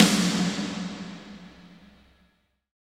Index of /90_sSampleCDs/Roland - Rhythm Section/KIT_Drum Kits 8/KIT_Reverb Kit
SNR VERBY02L.wav